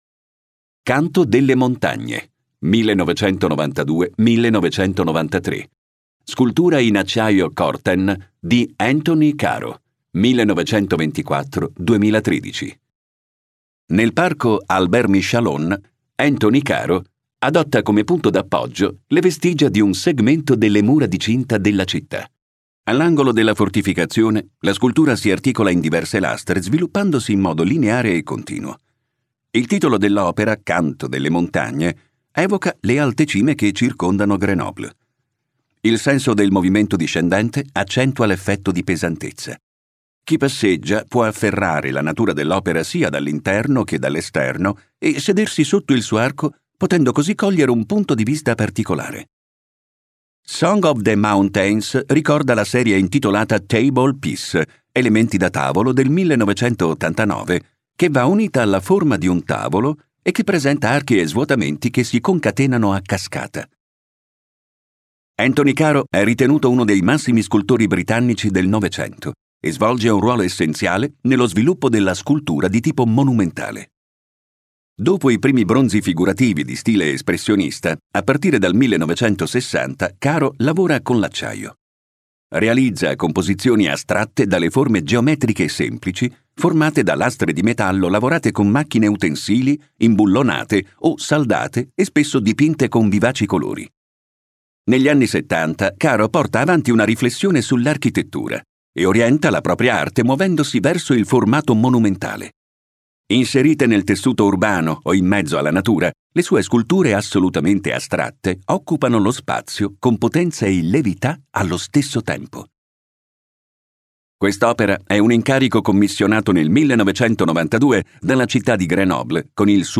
Audio guide